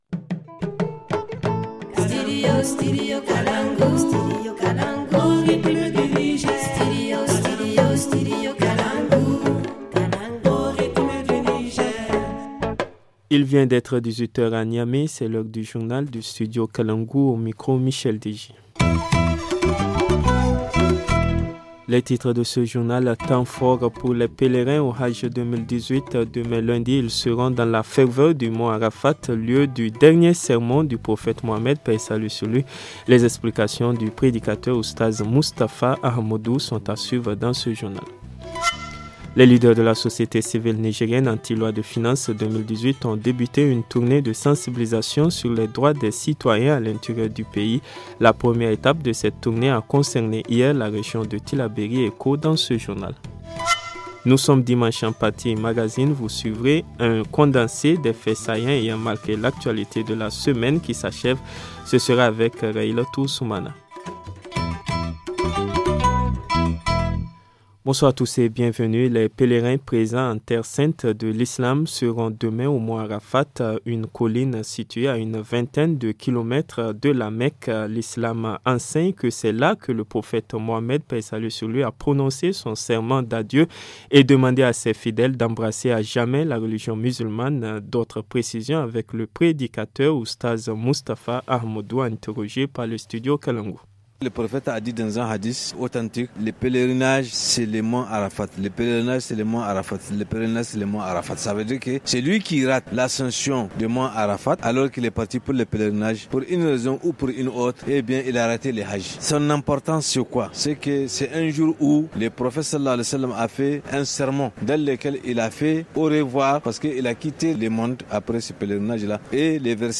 Le journal du 19 août 2018 - Studio Kalangou - Au rythme du Niger